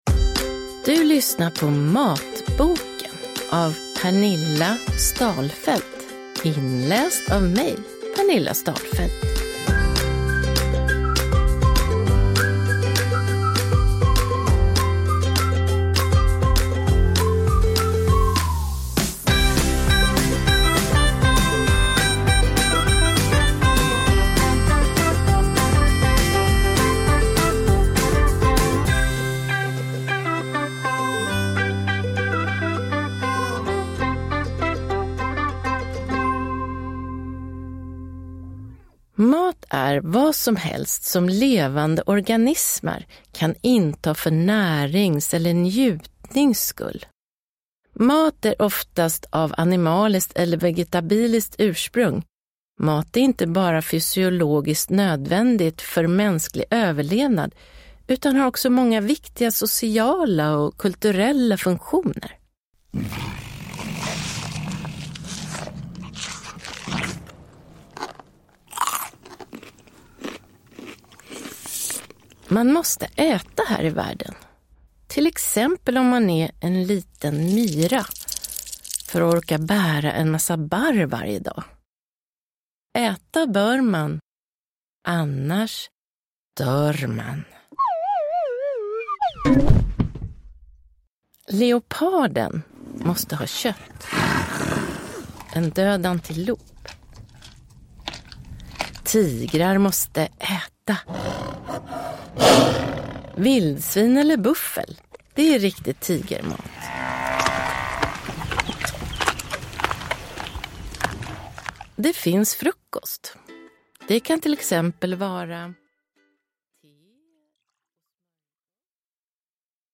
Matboken – Ljudbok